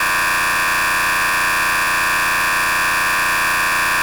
rainbow_laser.ogg